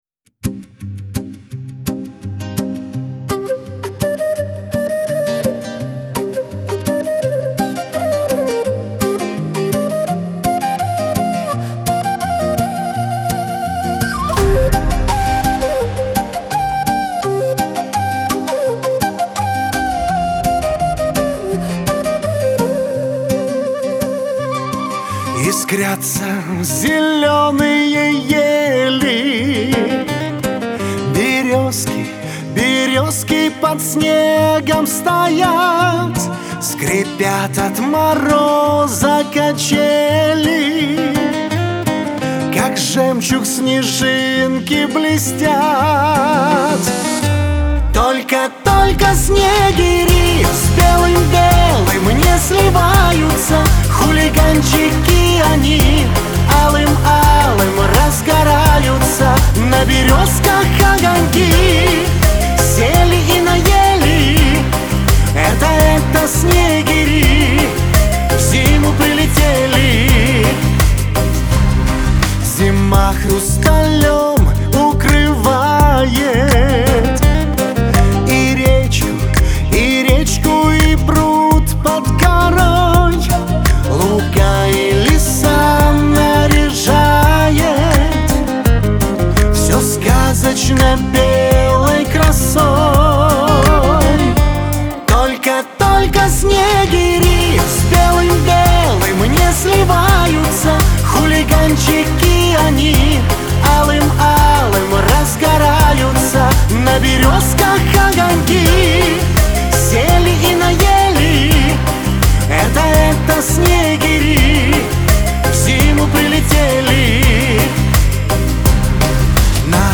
Шансон , Лирика
диско